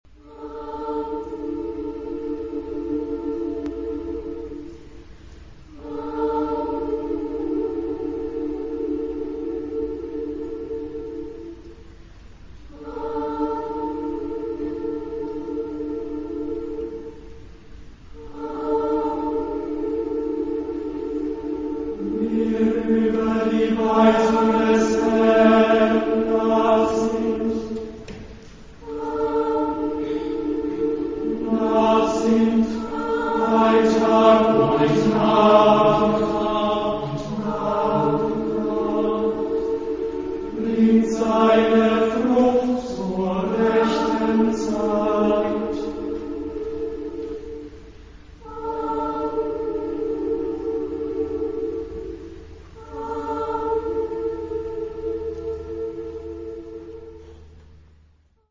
Genre-Style-Forme : Sacré ; Chœur
Caractère de la pièce : calme ; lent
Type de choeur : SSAATTBB  (8 voix mixtes )
Tonalité : la (centré autour de)